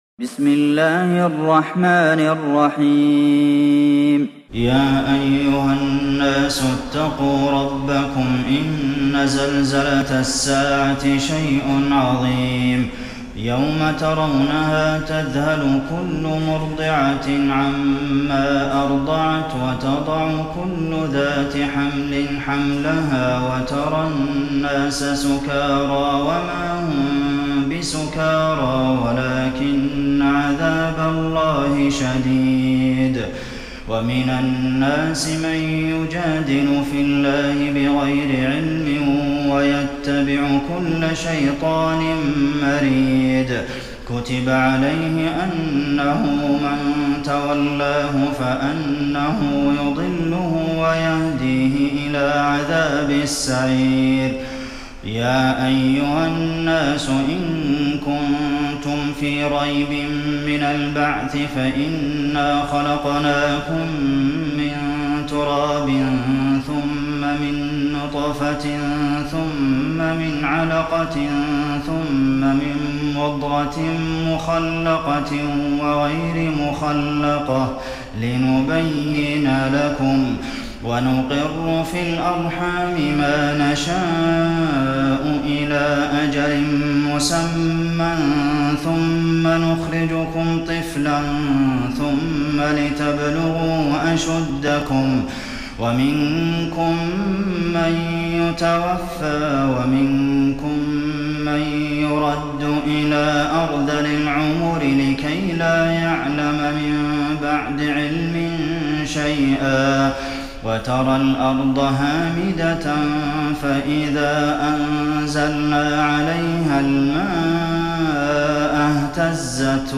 تراويح الليلة السادسة عشر رمضان 1430هـ سورة الحج كاملة Taraweeh 16 st night Ramadan 1430H from Surah Al-Hajj > تراويح الحرم النبوي عام 1430 🕌 > التراويح - تلاوات الحرمين